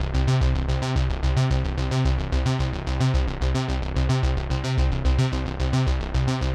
Index of /musicradar/dystopian-drone-samples/Droney Arps/110bpm
DD_DroneyArp4_110-C.wav